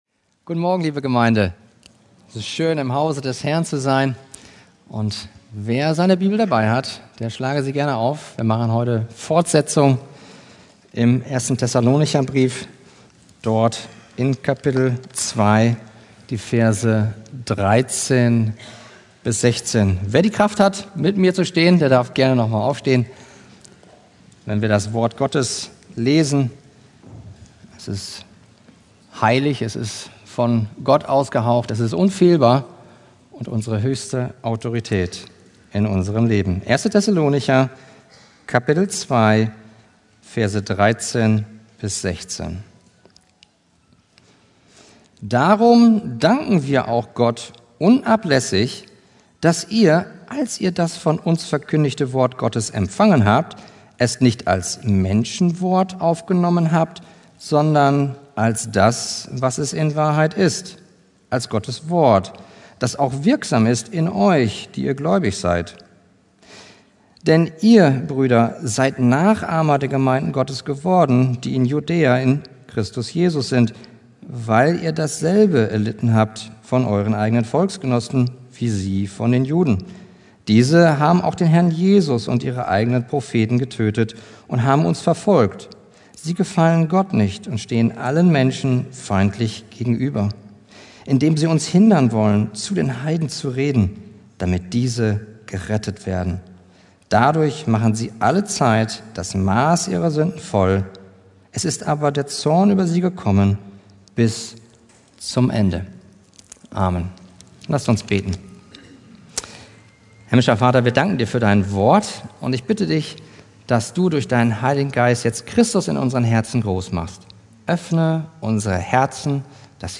Predigttext: 1.Thessalonicher 2,13-16